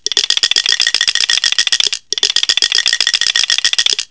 4.2.2.1.CẶP KÈ hay SÊNH SỨA
Lắc: Ví dụ: (472-5a)